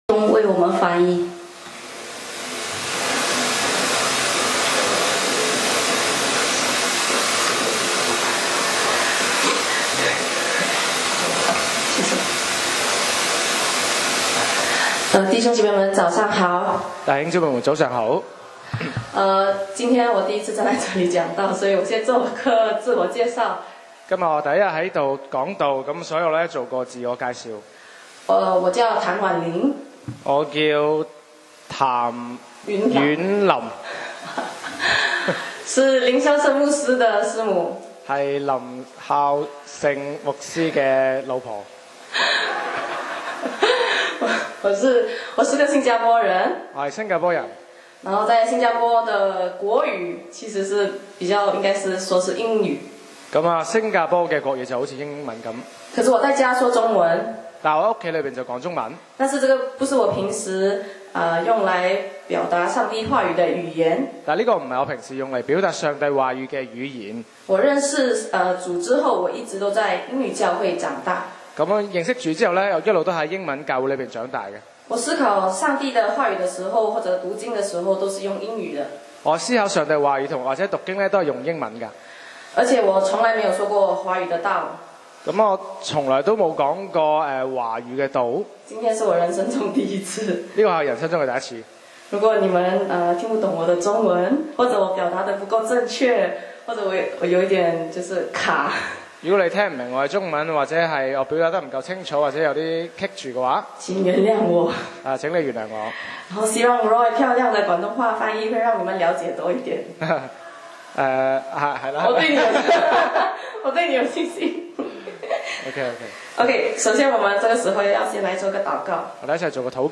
講道 Sermon 題目 Topic：祂记得我是尘土，我记得祂是慈爱 經文 Verses：诗篇103. 1（大卫的诗。）